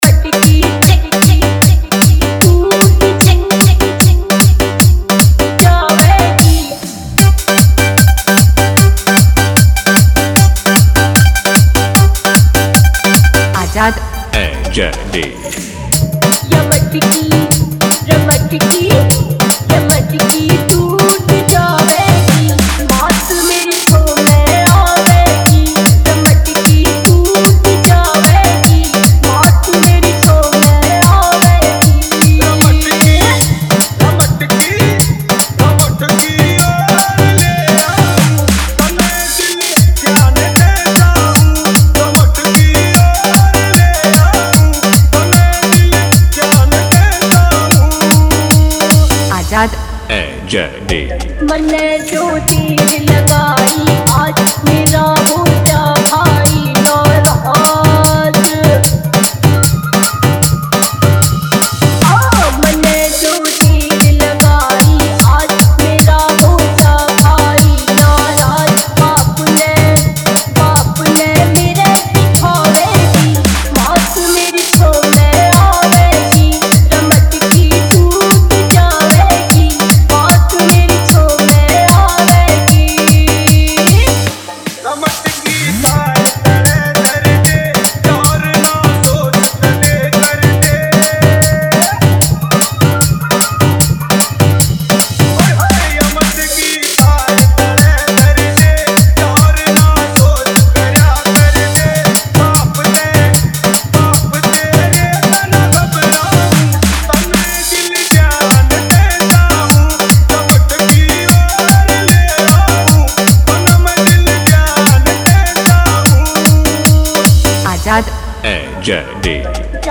Janmashtami Special Dj Remix
Bhakti Dance Remix Mp3 Song, Krishna Bhajan Dj Remix Song